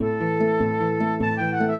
flute-harp
minuet10-6.wav